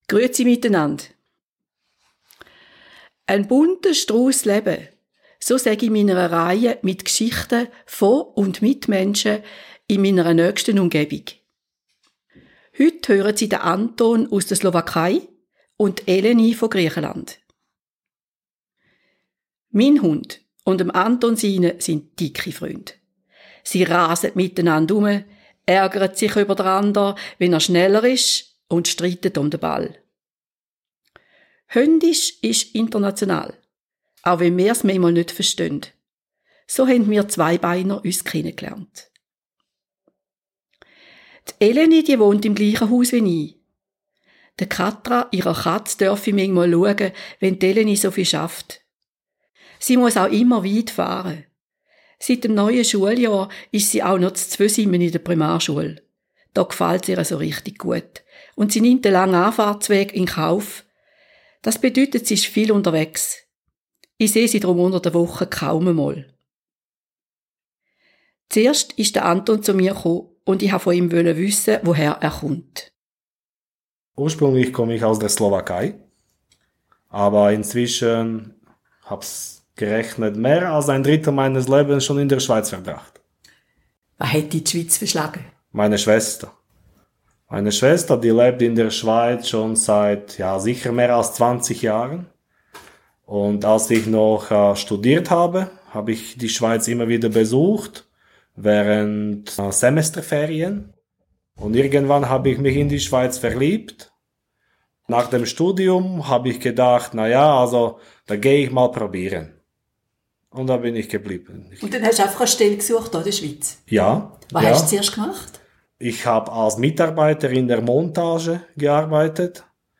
Diesmal mit einem Menschen mit gutem Herzen und Ingenieur aus der Slovakei und einer Lehrerin aus Griechenland, ursprünglich aus Zypern.